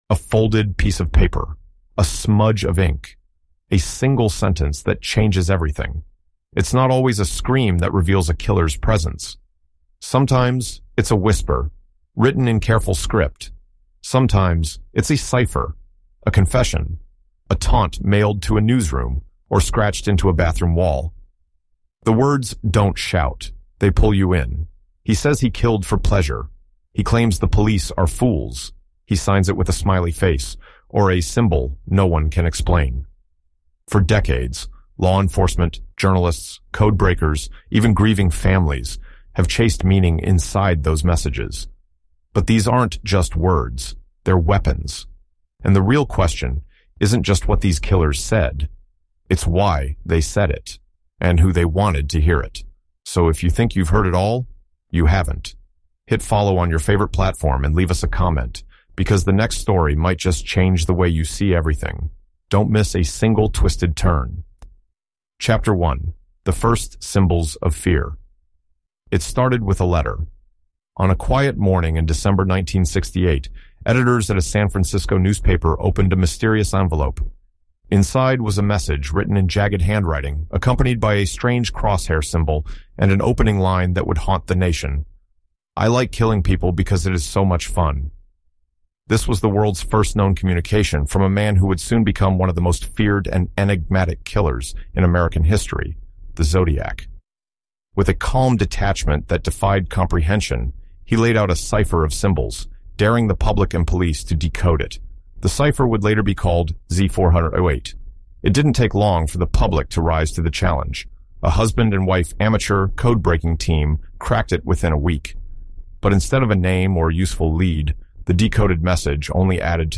From the Zodiac’s cryptic ciphers to the Happy Face Killer’s taunting letters, this investigative audio documentary explores how killers use language to manipulate, control, and continue their crimes long after the act. Through courtroom battles, psychological decoding, and emotional fallout, discover why some confessions do more harm than closure—and what we can learn when we finally stop listening.